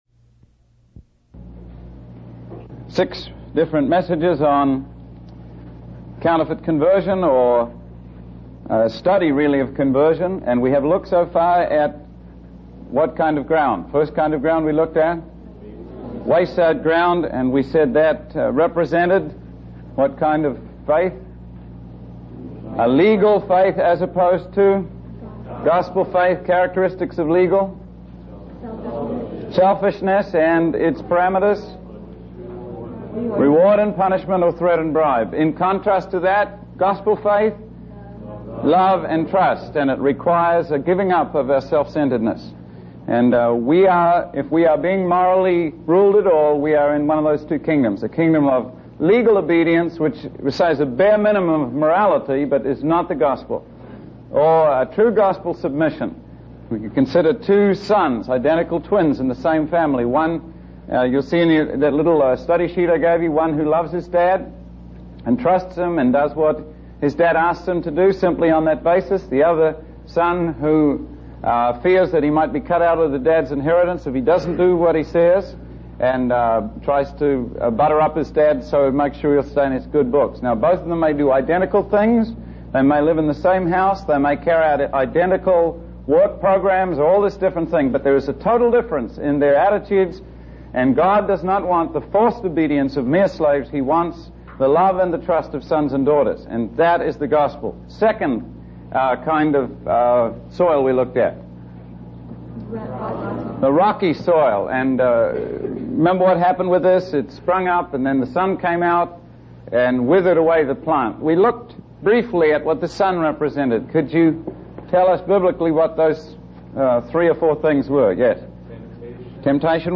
In this sermon, the preacher discusses the importance of not allowing worldly desires and material possessions to choke out the word of God in our lives. He emphasizes the need for Christians to remain fruitful and not lose their first love for God.